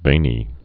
(vānē)